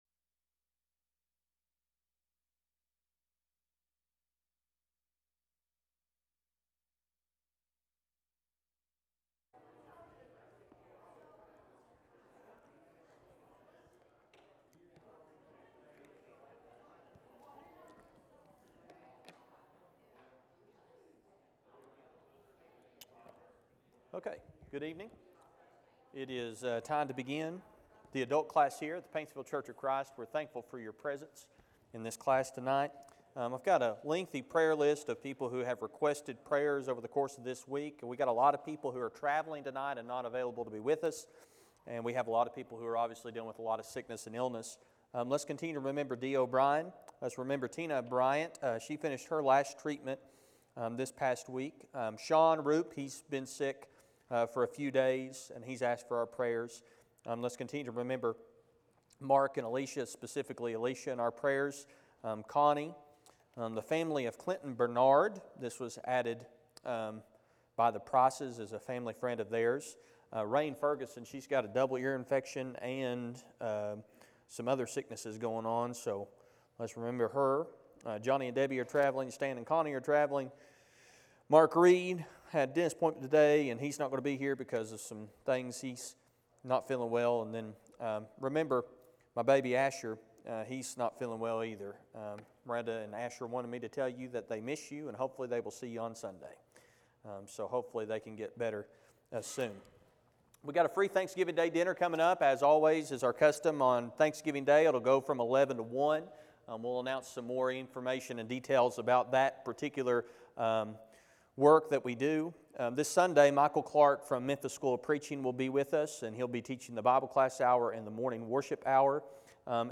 The sermon is from our live stream on 11/6/24